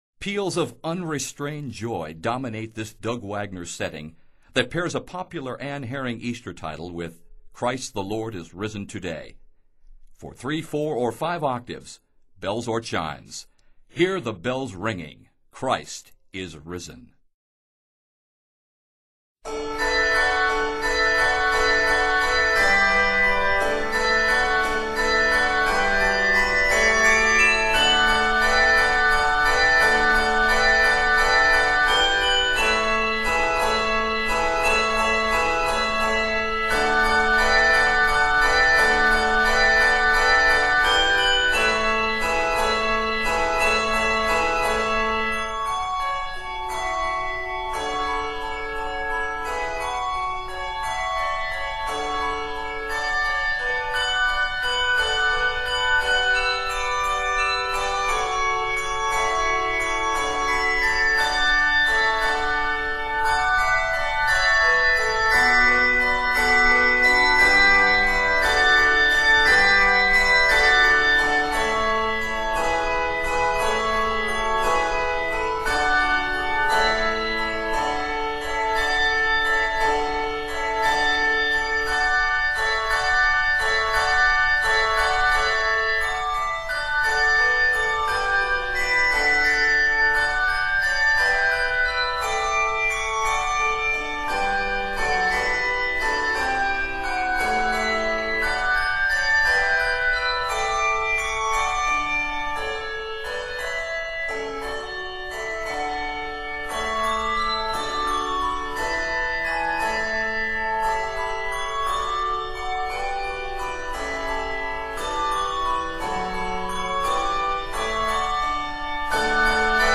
is arranged in D Major